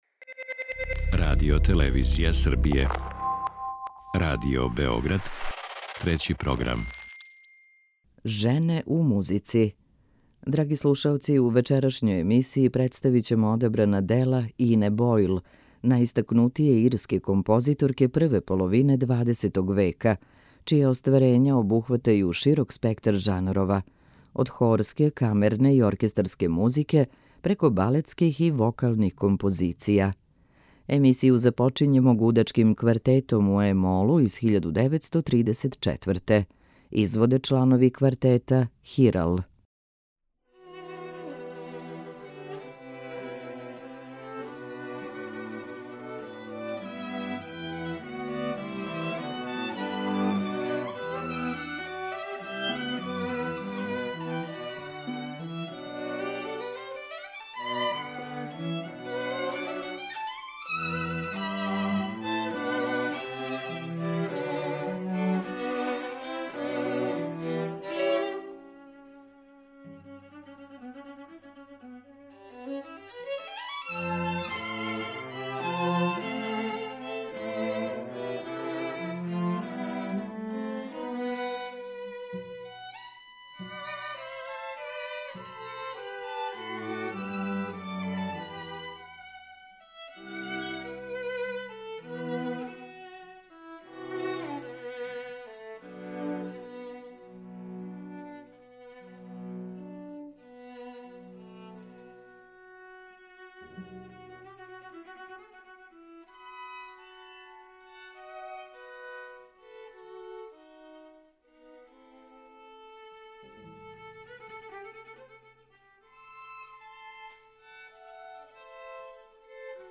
Дела Ине Бојл, најистакнутије ирске композиторке прве половине XX века, чија остварења обухватају широк спектар жанрова: од хорске, камерне и оркестарске музике, преко балетских и вокалних композиција. Слушаћете Гудачки квартет у е молу, концертну рапсодију Чаробна харфа и Виолински коцерт.